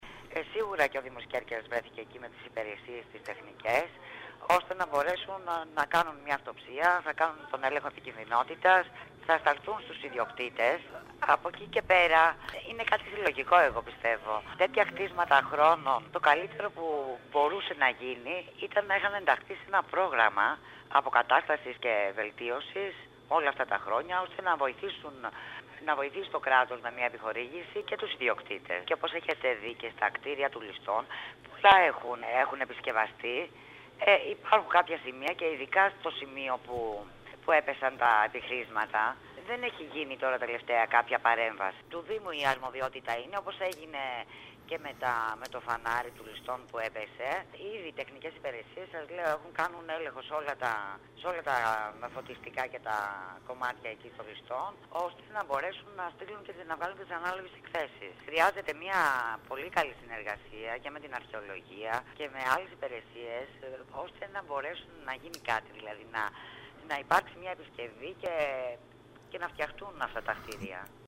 Έκθεση επικινδυνότητας καταρτίζουν οι τεχνικές υπηρεσίες του Δήμου Κέρκυρας για το κτήριο του Λιστόν, ύστερα από την πτώση επιχρισμάτων που σημειώθηκε χθες. Μιλώντας σήμερα στην ΕΡΤ Κέρκυρας η αντιδήμαρχος πόλης Νόνη Μάστορα είπε ότι τη μόνη παρέμβαση που μπορεί να κάνει ο Δήμος στα ιδιωτικά αυτά κτήρια είναι να επιστήσει την προσοχή στους ιδιοκτήτες ότι πρέπει να τα συντηρήσουν.